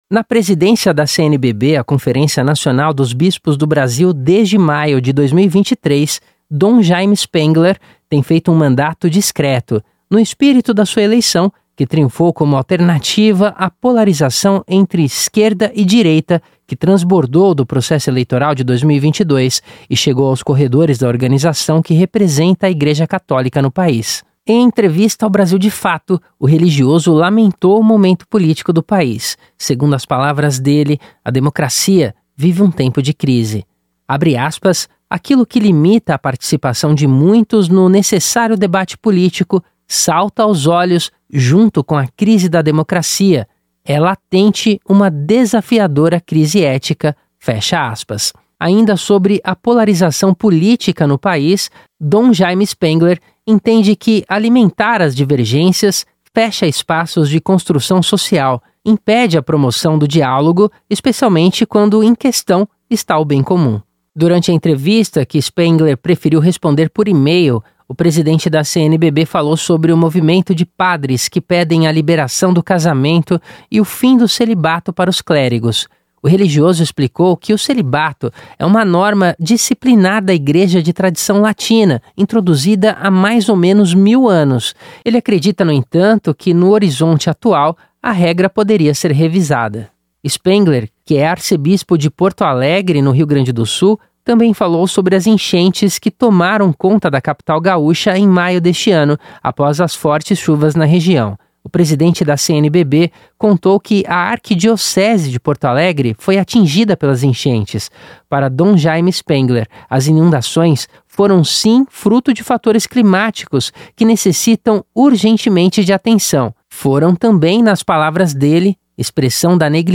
Exclusivo ‘Democracia vive um tempo de crise’, diz dom Jaime Spengler, presidente da CNBB Em entrevista ao Brasil de Fato, religioso admite que celibato para padres poderia ser “revisado” e “revogado”